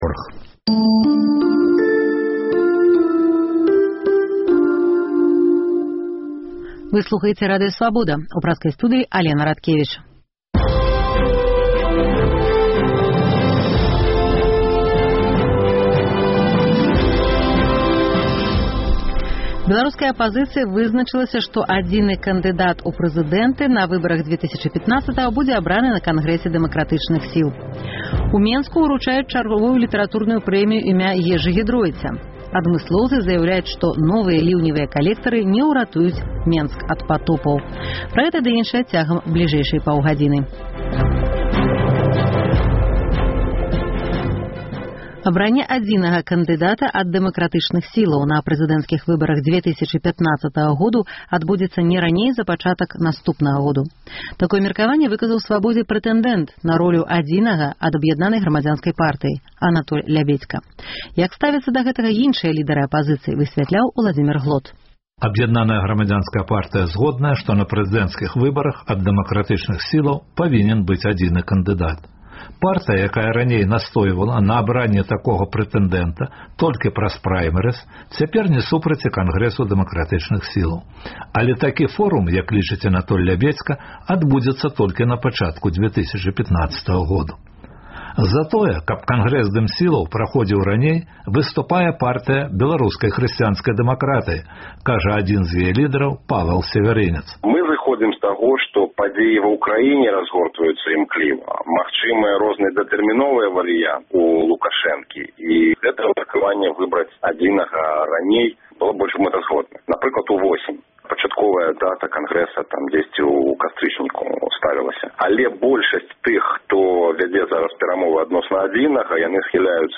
У праграме – прэзэнтацыя песьні, а таксама гутарка пра літаратурныя прэміі, прагноз адносна адзінага кандыдата ад апазыцыі, погляд на нацыянальную кухню вачыма замежнікаў і беларусаў.